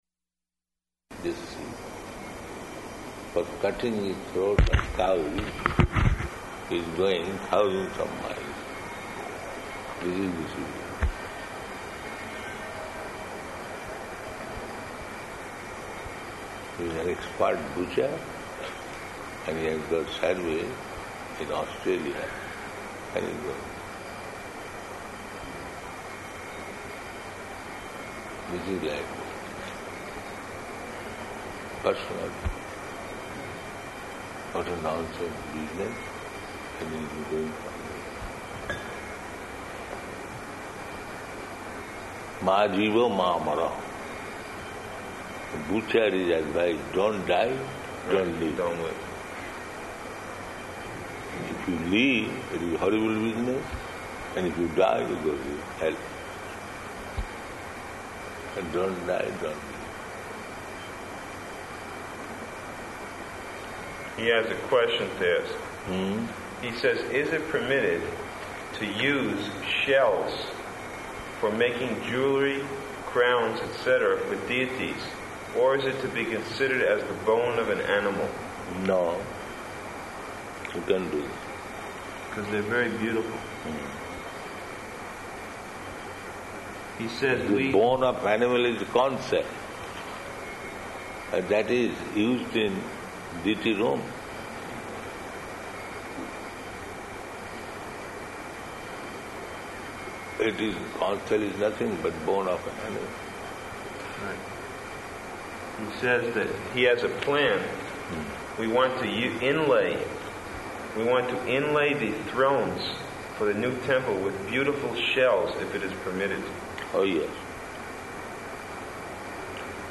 Room Conversations
Location: Vṛndāvana